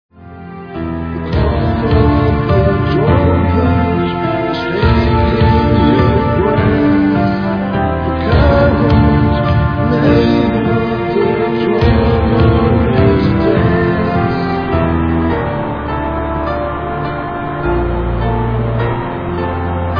'dark neo-classical/industrial'